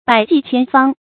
百計千方 注音： ㄅㄞˇ ㄐㄧˋ ㄑㄧㄢ ㄈㄤ 讀音讀法： 意思解釋： 想盡或用盡一切辦法。